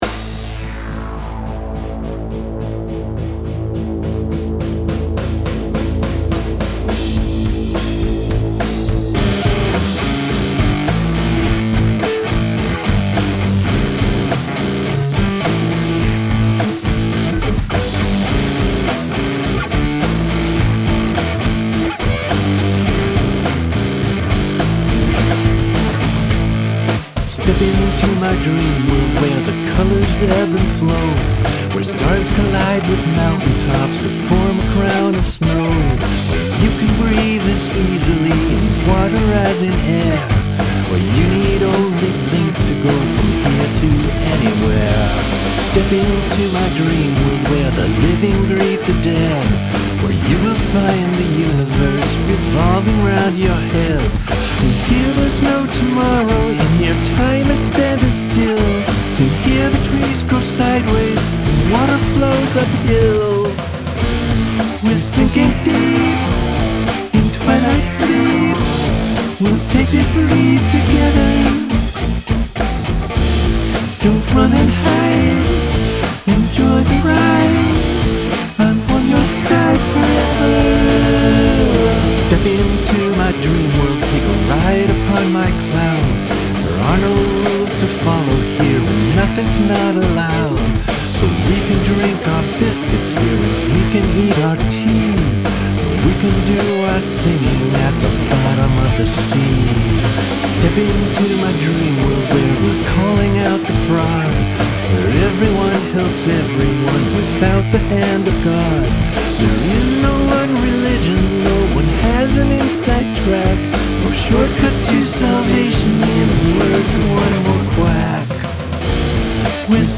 Here's a metal song with psychedelic lyrics.